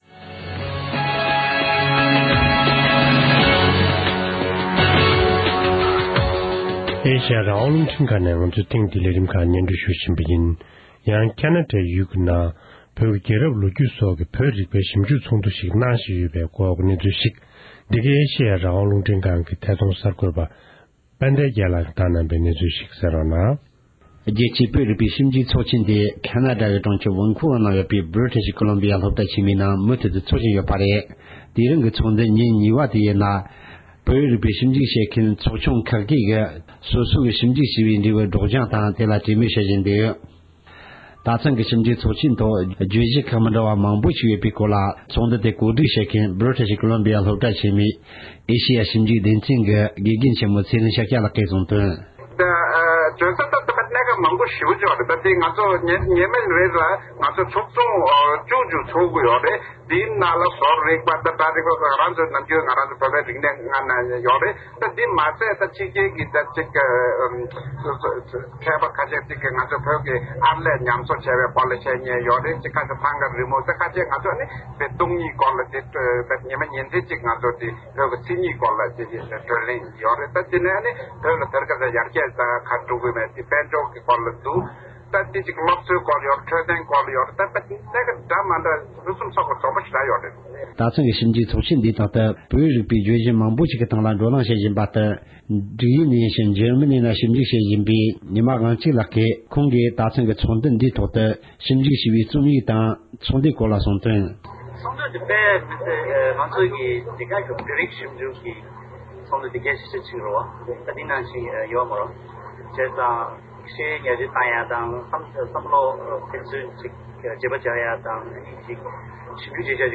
ས་གནས་ས་ཐོག་ནས་བཏང་འབྱོར་བྱུང་བའི་གནས་ཚུལ་ཞིག་ལ་གསན་རོགས༎